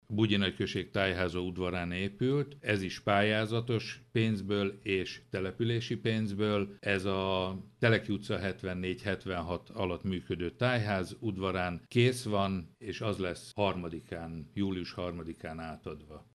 Nagy András Gábort, Bugyi polgármesterét hallják.